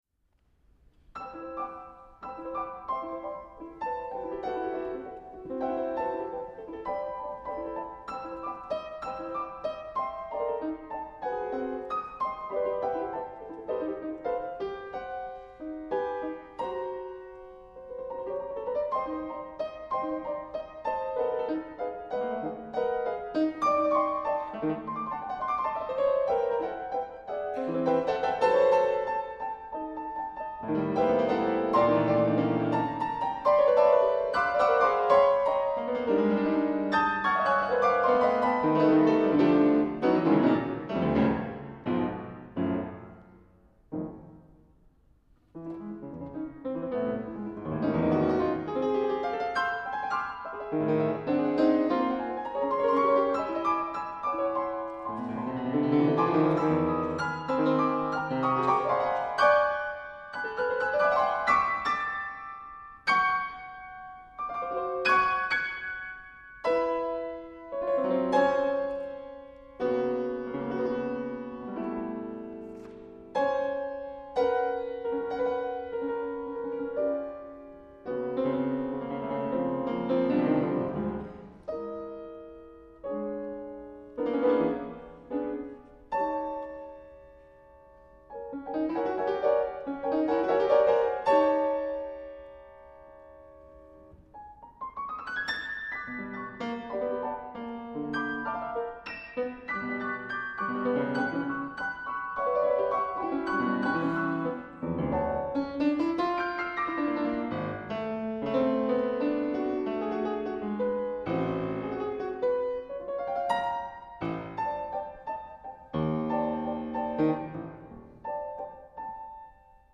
live performance mp3